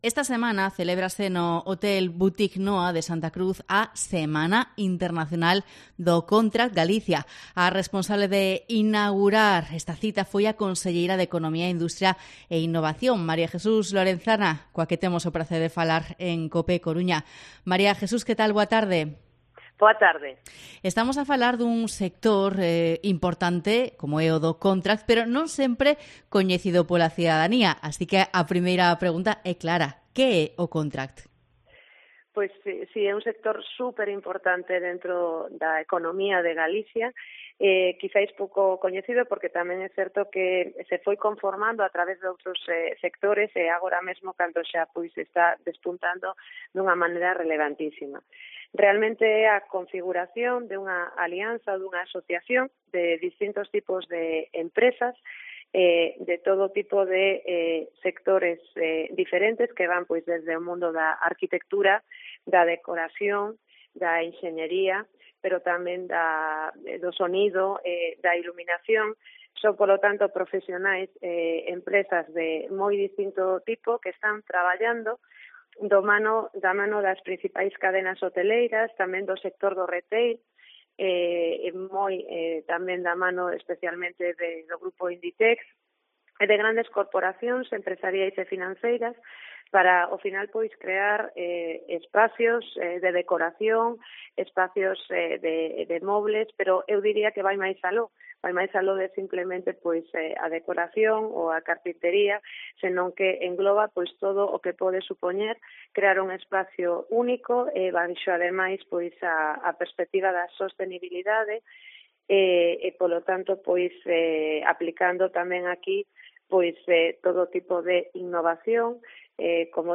AUDIO: Entrevista coa conselleira de Economía, María Jesús Lorenzana, sobre o sector do Contract en Galicia